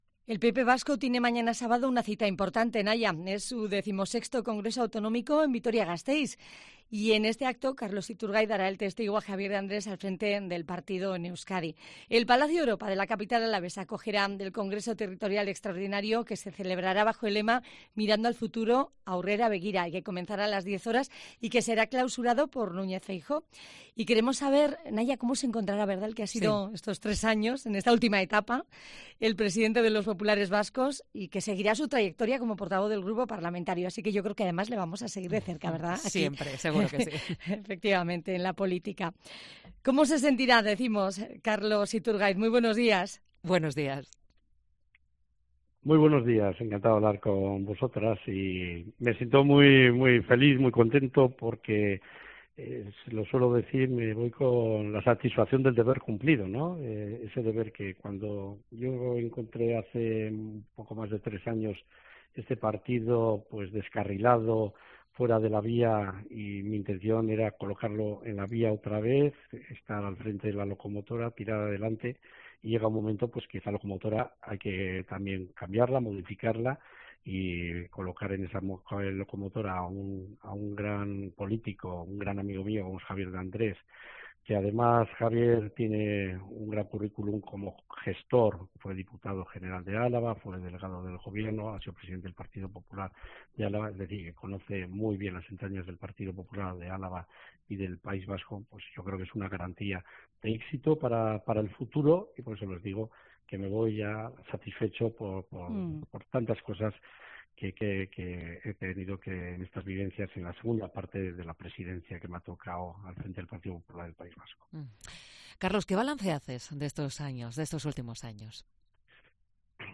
Hoy en COPE Euskadi , el aún presidente del PP vasco, Carlos Iturgaiz , nos ha concedio una entrevista más personal en la que ha hablado sobre su satisfacción al haber cumplido los objetivos que lo llevaron a liderar el PP en el País Vasco en esta última etapa.